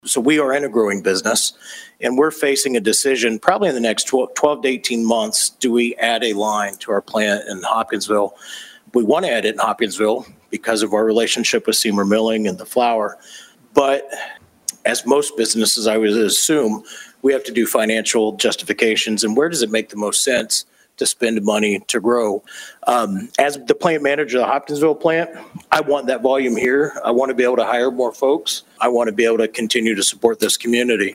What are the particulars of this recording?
Agriculture leaders and related industries addressed Hopkinsville City Council about the issue Tuesday night.